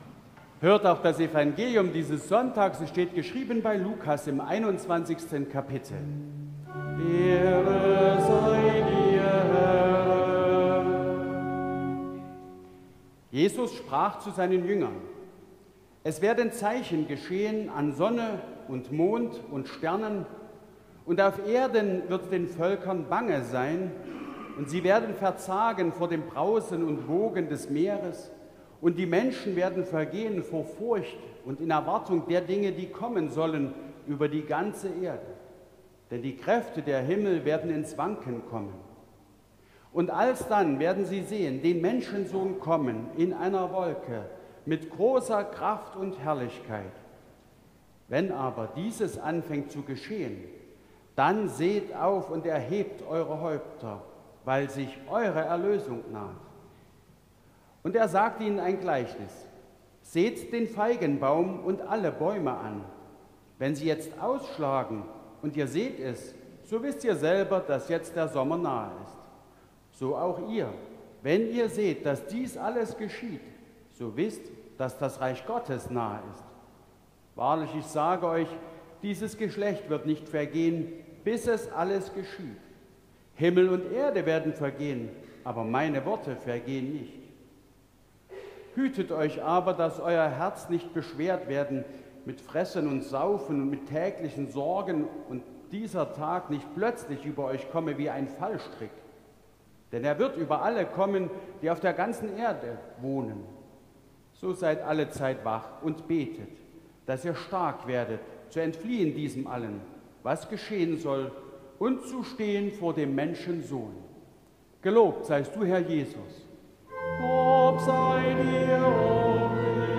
Lesung aus Lukas 21,25-36 Ev.-Luth.
Audiomitschnitt unseres Gottesdienstes vom 1.Avent 2024